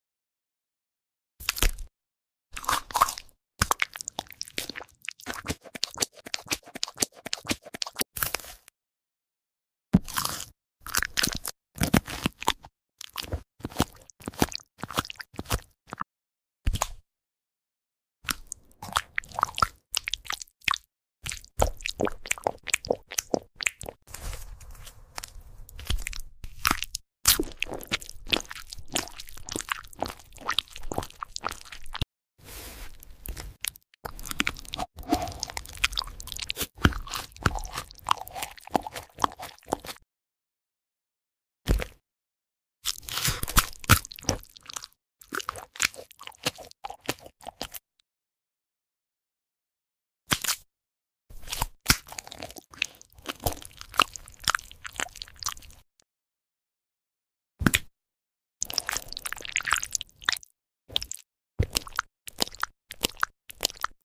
fruit eating fruit 🥑🍅 Make sound effects free download